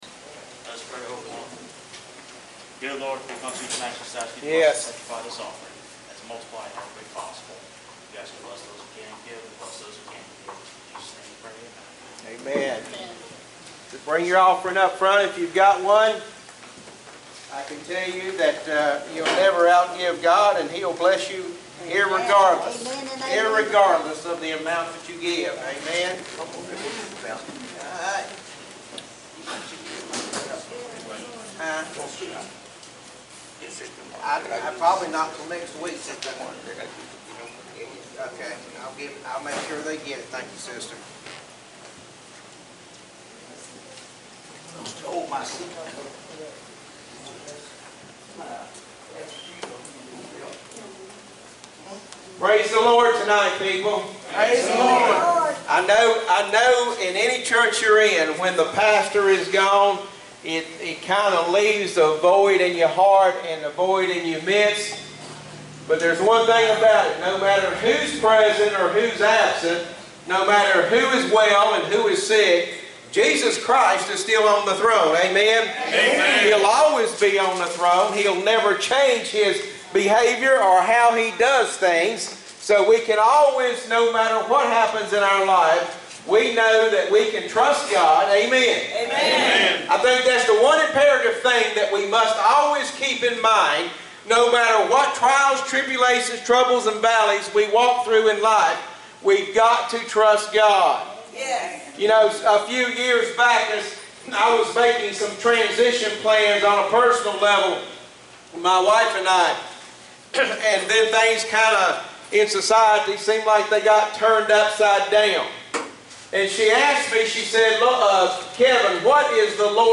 Sunday night service United Christian Inner City Ministries 09/17/2023